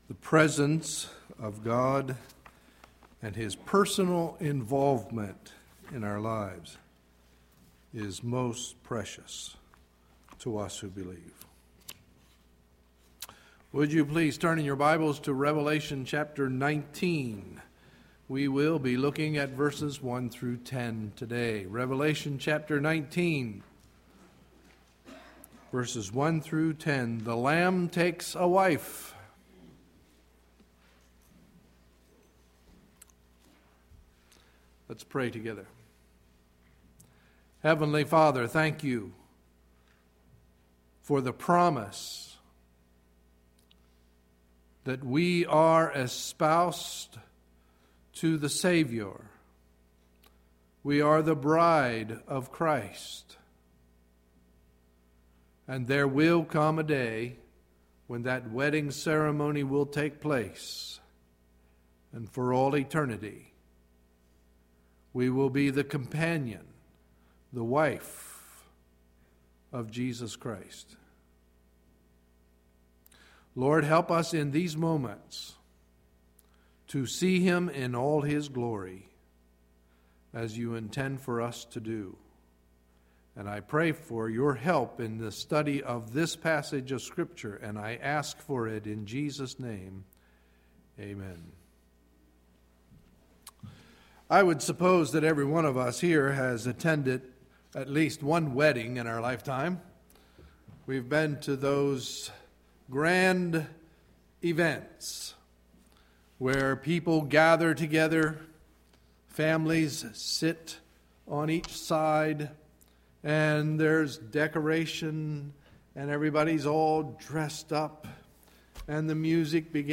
Sunday, November 13, 2011 – Morning Message